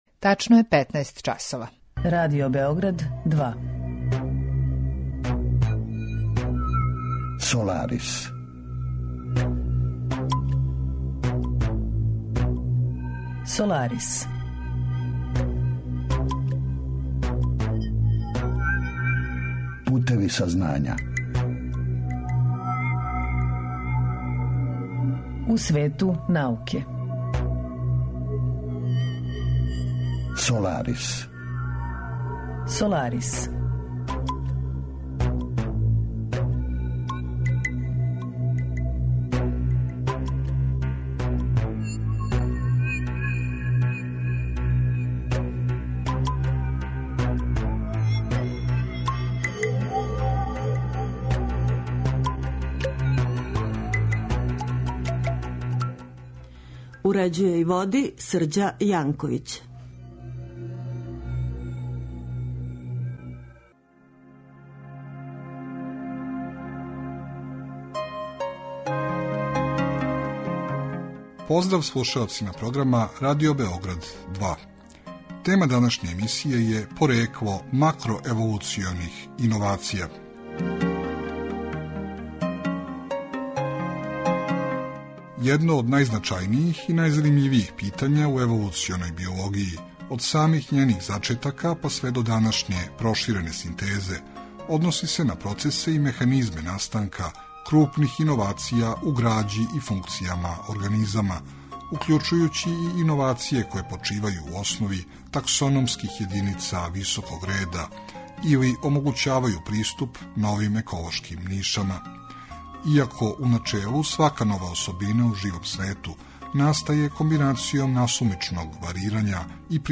Разговор је први пут емитован 26. маја 2019. године.